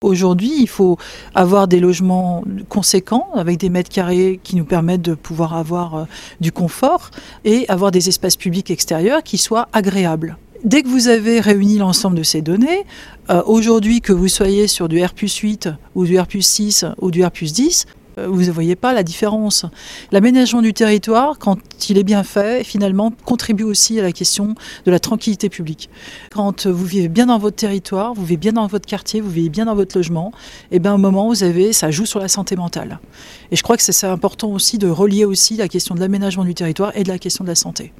Les explications de Nora Segaud-Labidi, adjointe en charge de l’aménagement durable et de l’habitat: